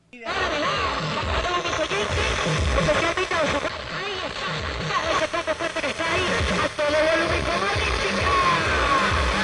收音机电台干扰的声音
描述：无线电收音机拾取多个电台的声音。
标签： 干扰 静电 无线电 上午无线电 频率 噪声
声道立体声